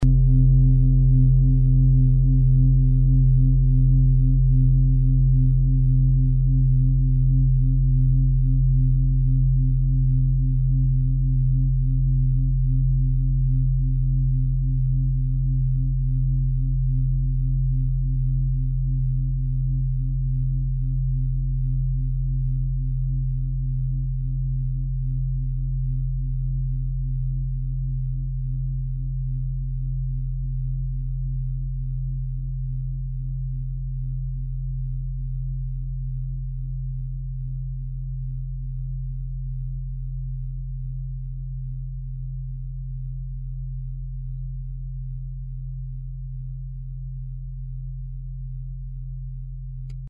Fuss-Klangschale Nr.10, Planetentonschale: Mars
Klangschalen-Gewicht: 8220g
Klangschalen-Durchmesser: 46,6cm
(Ermittelt mit dem Gummischlegel)
Die Klangschale hat bei 71.77 Hz einen Teilton mit einer
Die Klangschale hat bei 213.86 Hz einen Teilton mit einer
Die Klangschale hat bei 411.86 Hz einen Teilton mit einer
fuss-klangschale-10.mp3